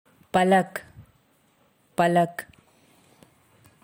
इस शब्द का उच्चारण कैसे करे (Pronunciation of Palak) = पलक